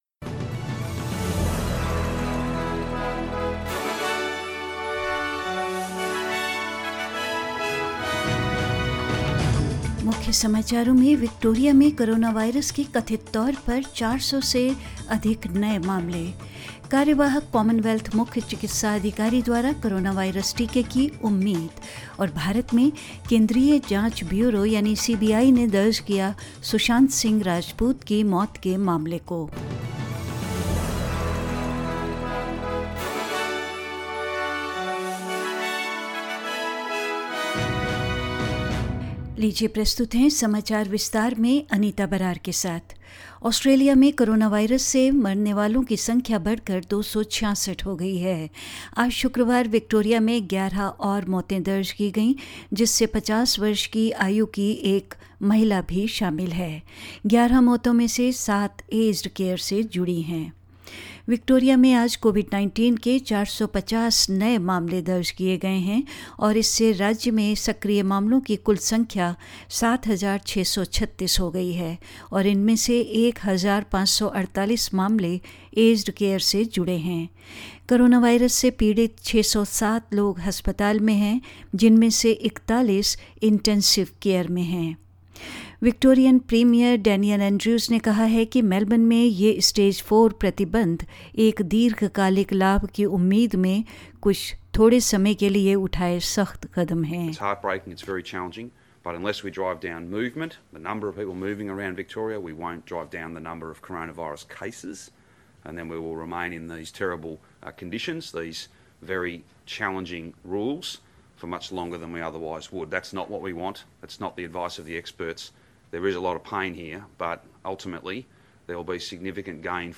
Hindi News 7th August 2020